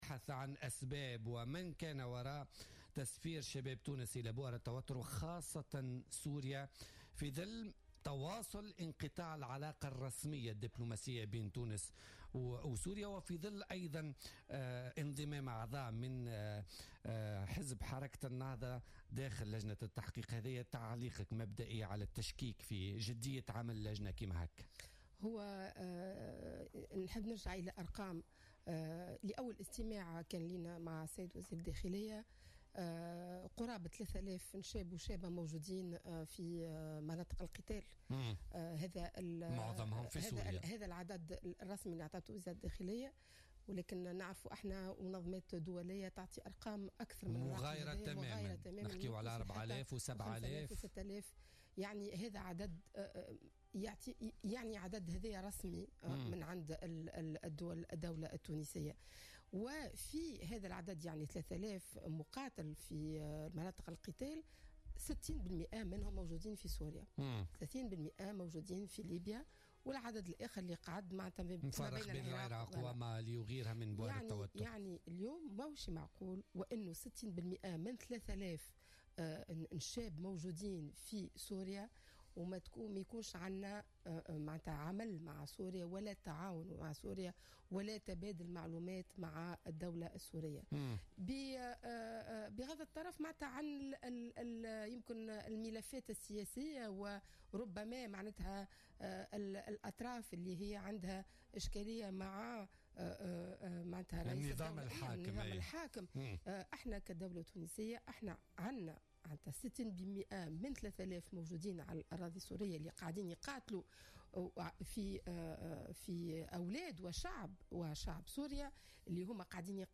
أكدت رئيسة لجنة التحقيق في شبكات تسفير الشباب إلى بؤر التوتر بمجلس نواب الشعب ليلى الشتاوي ضيفة بولتيكا اليوم الإثنين أن قرابة 3 آلاف شاب وشابة موجودين في مناطق القتال حسب أرقام رسمية صرح بها وزير الداخلية خلال جلسة استماع له بالمجلس.